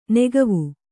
♪ negavu